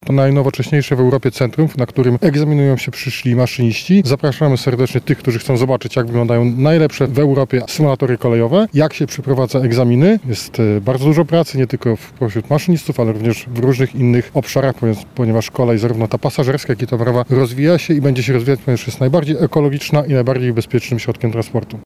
O szczegółach mówi wiceprezes Urzędu Transportu Kolejowego Marcin Trela.